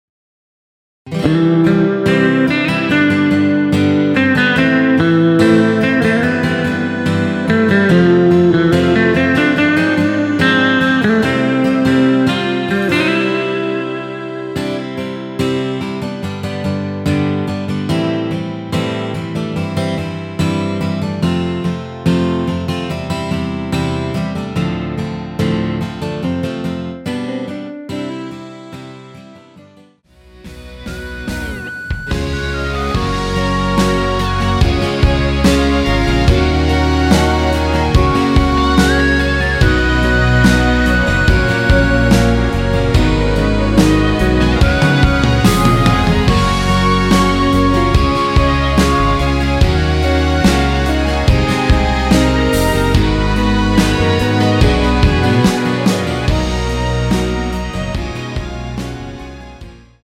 원키에서(-1)내린 MR입니다.
◈ 곡명 옆 (-1)은 반음 내림, (+1)은 반음 올림 입니다.
앞부분30초, 뒷부분30초씩 편집해서 올려 드리고 있습니다.
중간에 음이 끈어지고 다시 나오는 이유는